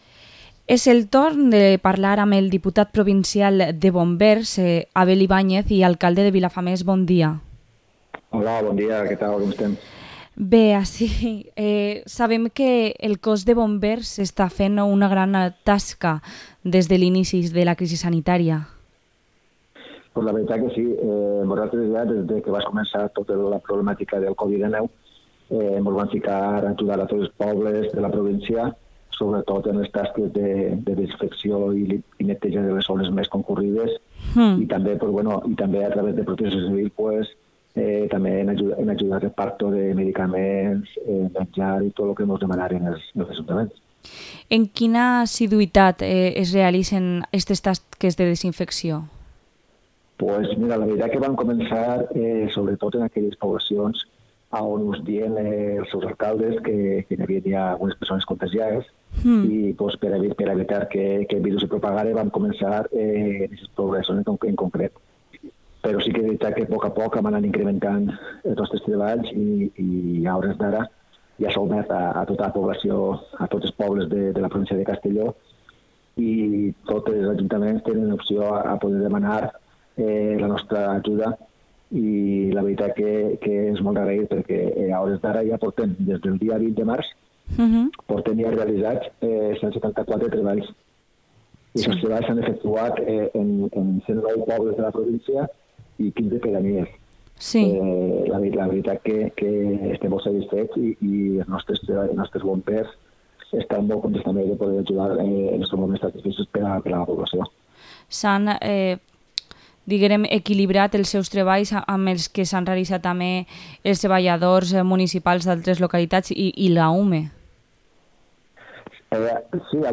Entrevista al diputado provincial de Bomberos, Abel Ibañez